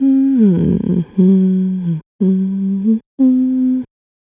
Ripine-Vox_Hum.wav